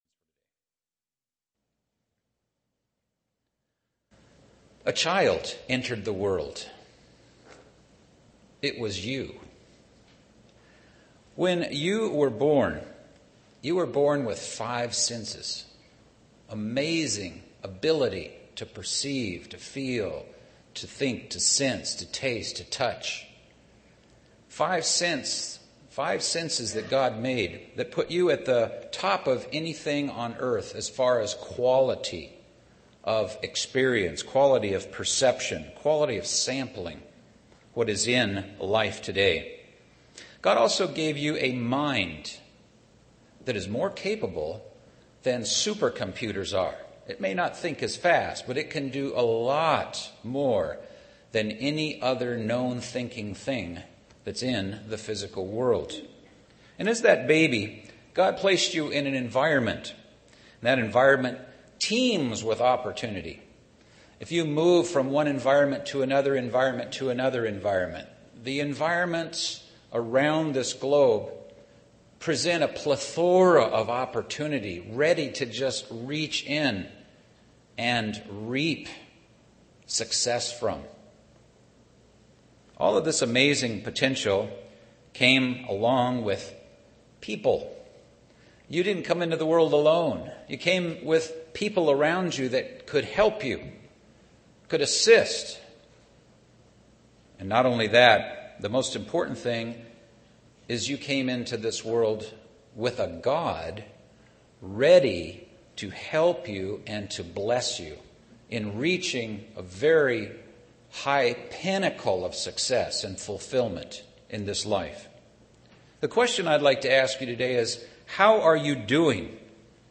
How to build your career, marriage, home, and spiritual life successfully UCG Sermon Transcript This transcript was generated by AI and may contain errors.